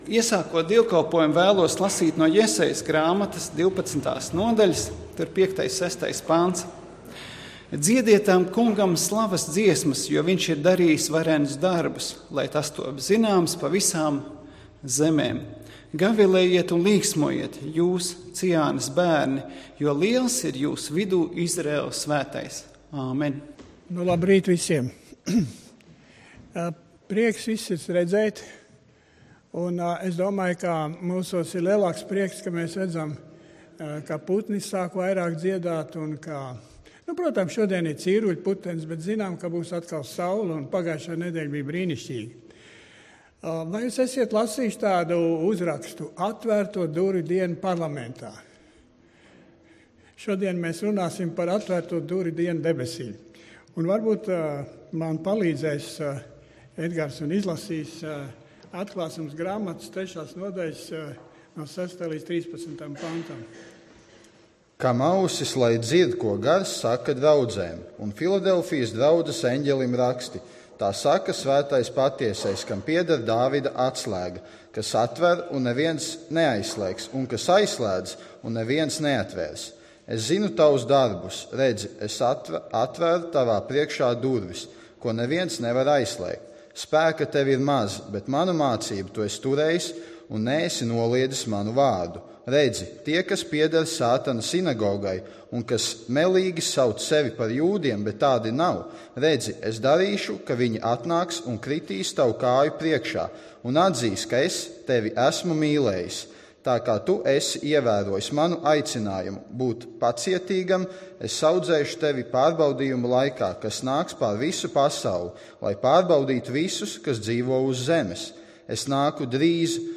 Dievkalpojums 14.03.2015: Klausīties
Svētrunas